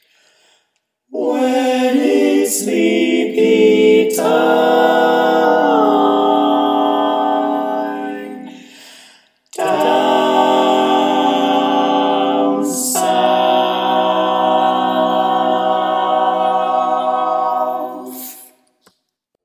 Key written in: C Major
How many parts: 5
Type: Barbershop
All Parts mix: